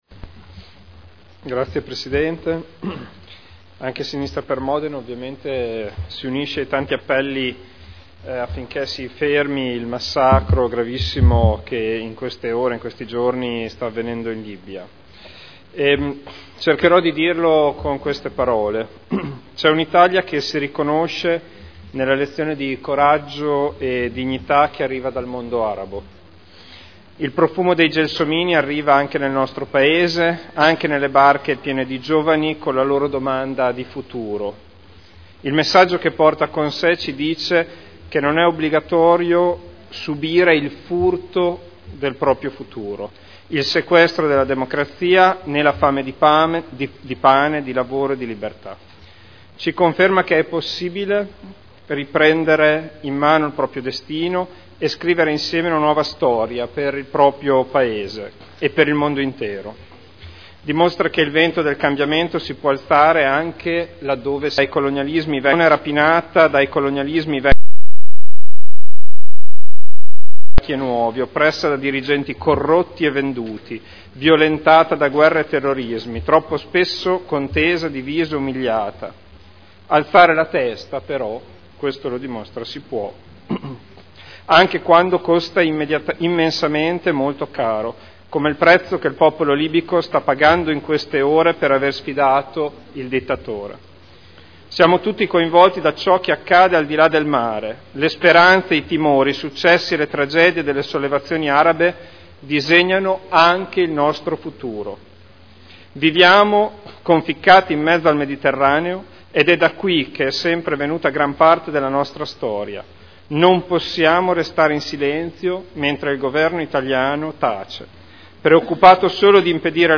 Federico Ricci — Sito Audio Consiglio Comunale
Seduta del 24/02/2011. Interviene sugli Ordini del Giorno sulla Libia.